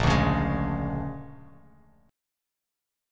A#7#9 chord